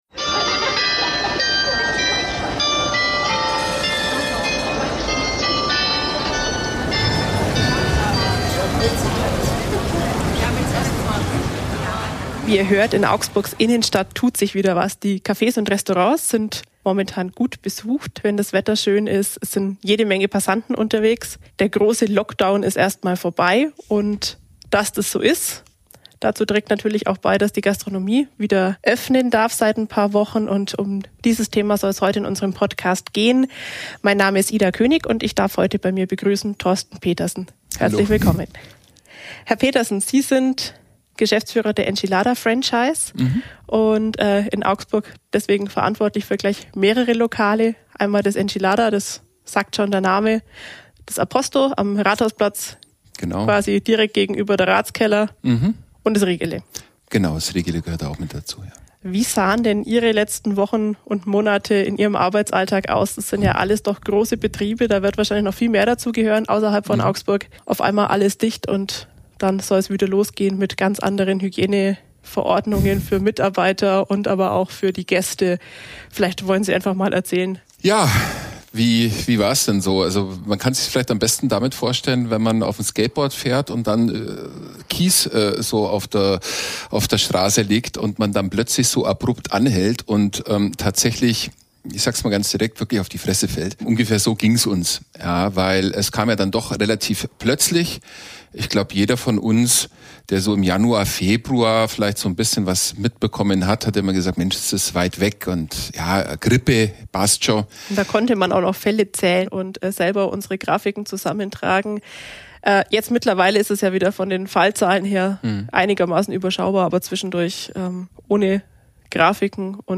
Außerdem kommen Gäste aus der Augsburger Innenstadt zu Wort, die ihre Erfahrungen mit den Corona-Beschränkungen in Cafés und Restaurants beschreiben.